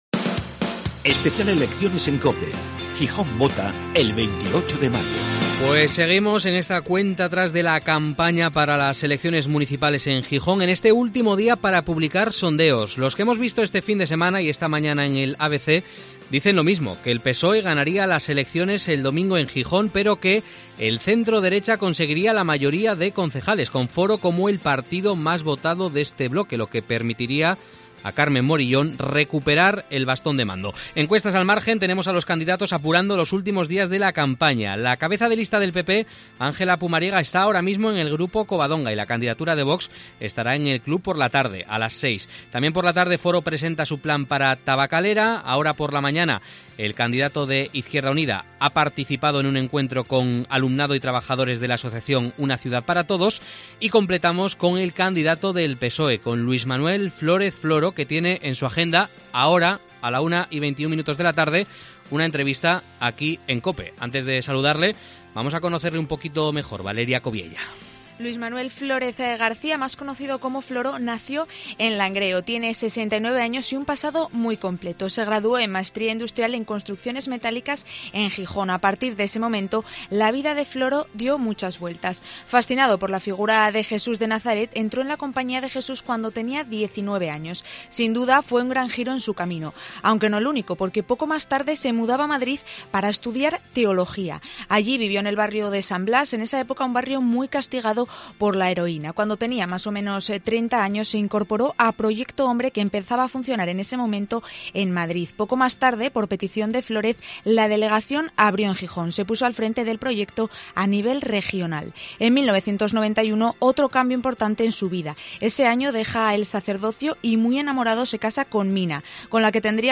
Gijón está en campaña electoral. En COPE hablamos con los candidatos, pero también escuchamos a los ciudadanos, sus inquietudes y peticiones.